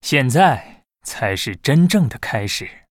文件 文件历史 文件用途 全域文件用途 Bhan_amb_05.ogg （Ogg Vorbis声音文件，长度2.9秒，104 kbps，文件大小：37 KB） 源地址:地下城与勇士游戏语音 文件历史 点击某个日期/时间查看对应时刻的文件。